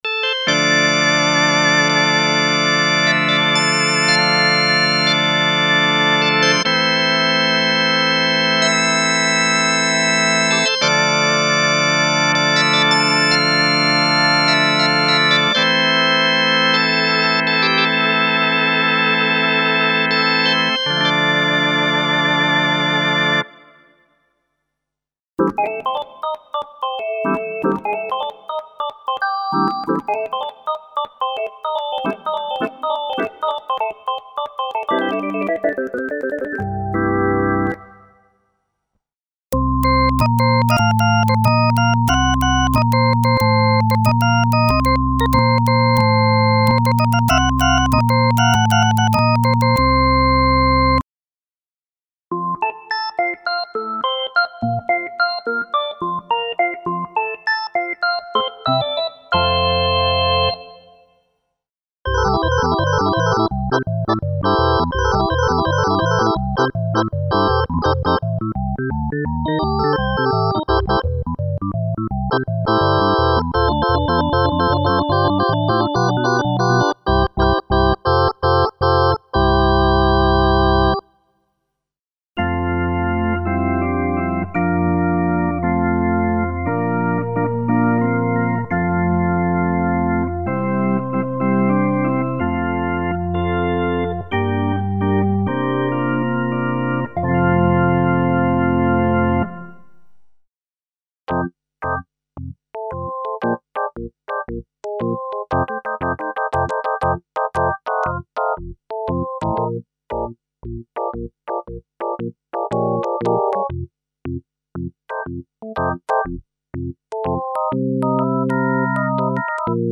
Electric organ, organ split and layers for various music styles, including a complete KB 3 Mode collection of drawbar organ emulations.
Info: All original K:Works sound programs use internal Kurzweil K2500 ROM samples exclusively, there are no external samples used.
K-Works - Electro Volume 2 - EX (Kurzweil K2xxx).mp3